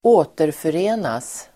Ladda ner uttalet
Uttal: [²'å:terföre:nas]